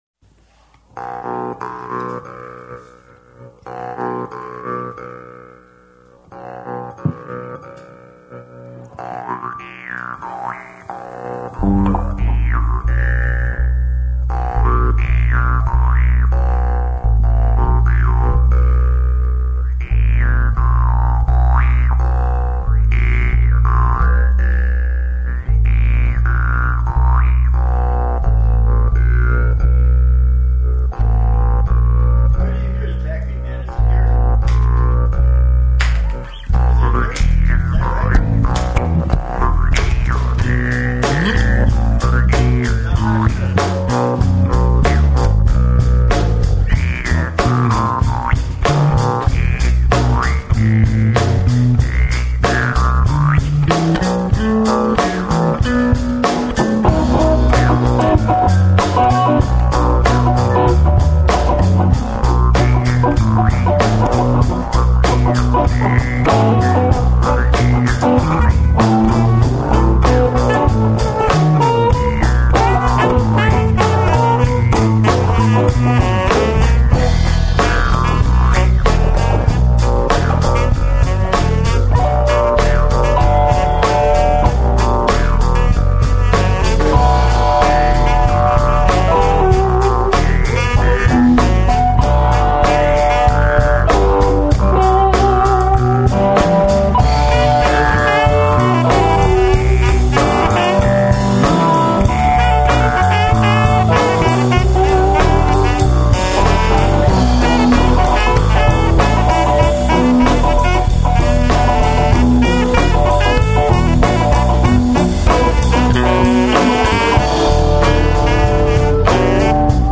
Low Flying Bee, is Straw Whistle's midwest version of instrumental music.
mouth harp
saxaphone
bass guitar
This song was performed and recorded live in the Star Bottling studio. No overdubs were done.